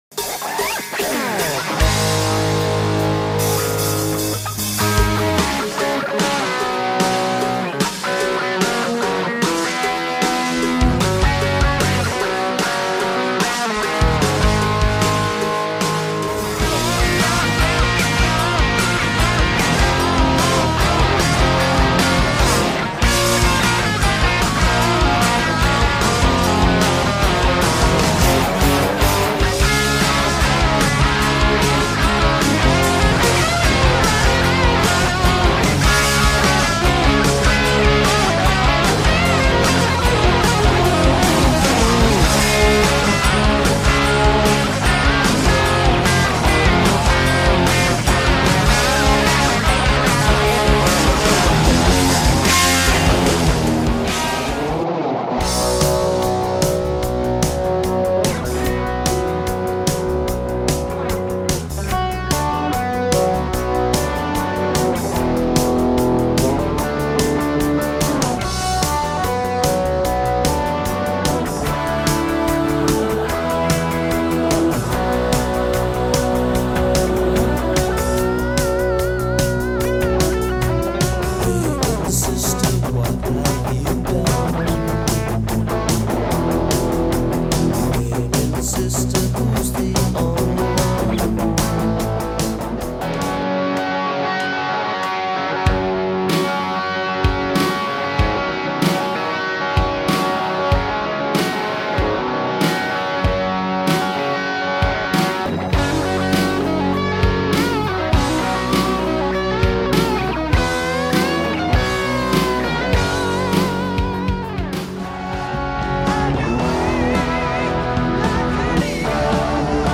Inserito in un Cicognani Powerload per poter aumentare il volume a livello 5, leggermente spinto con un Digitech Tone Driver. Gibson Les Paul Standard con pickup Slash Seymour Duncan APH 2S.